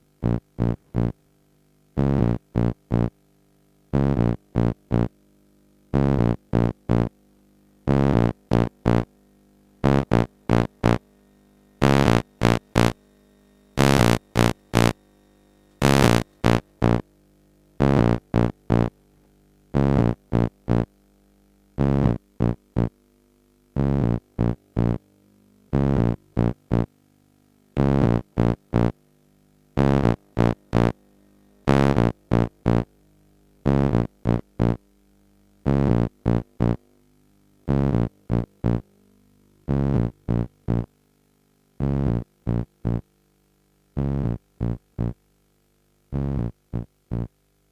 lowpassliuku.ogg